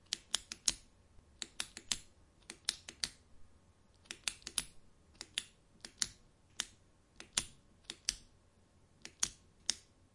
钢笔点击